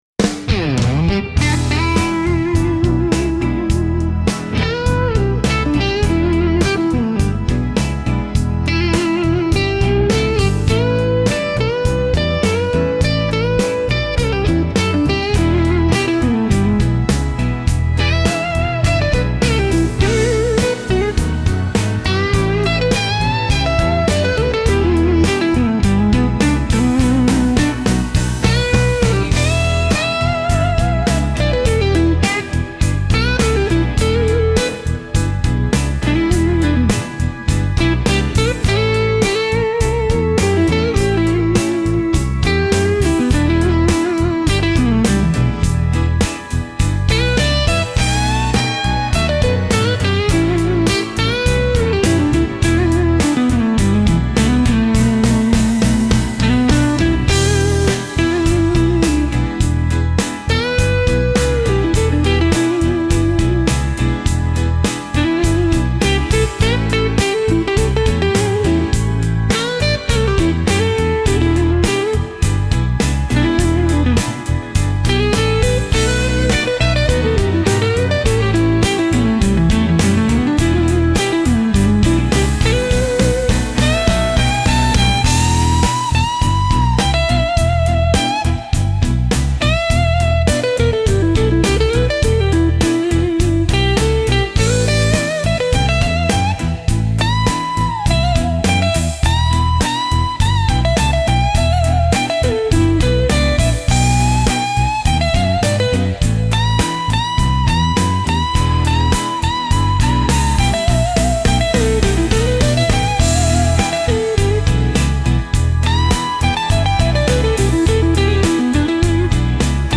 Instrumental - RLMradio Dot XYZ - 2.26.mp3 - Grimnir Radio